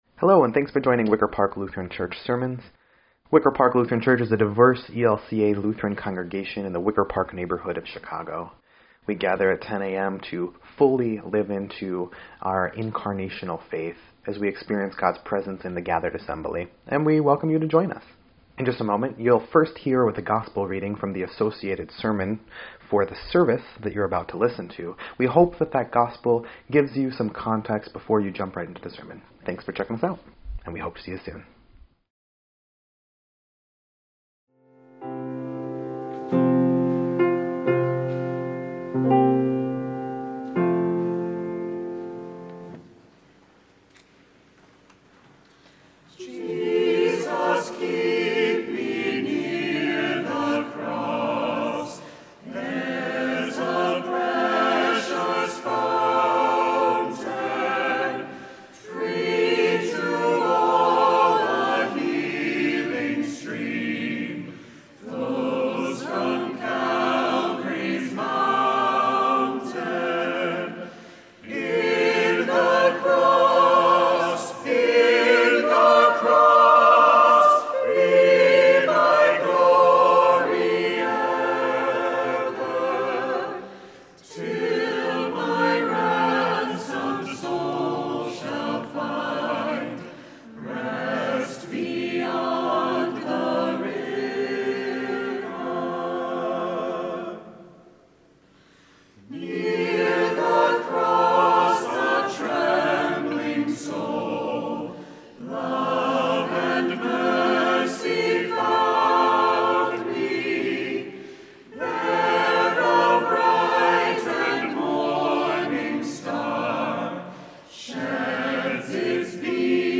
4.3.26-Sermon_EDIT.mp3